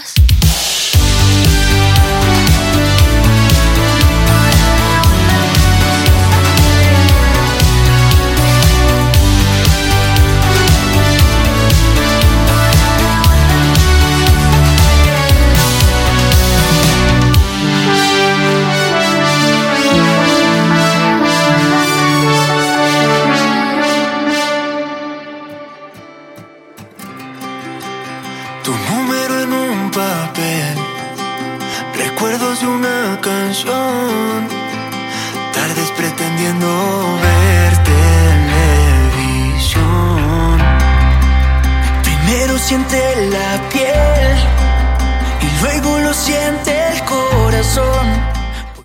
Programació musical